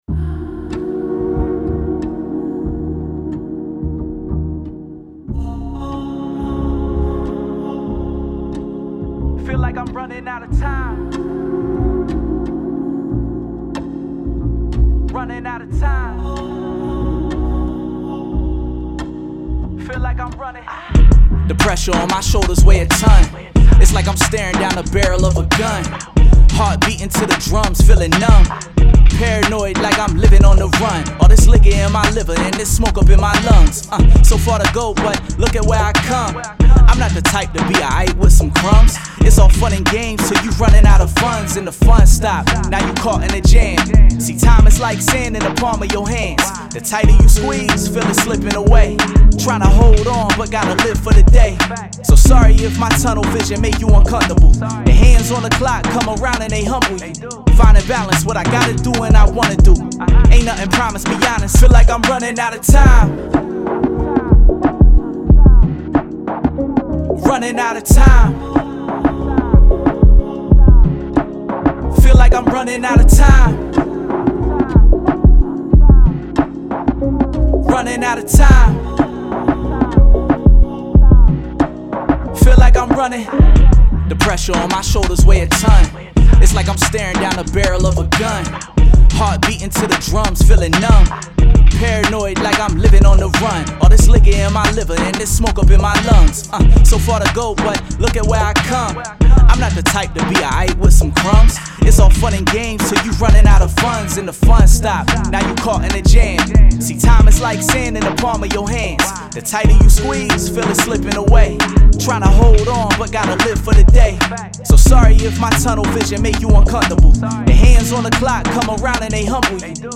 Hip Hop
D#Min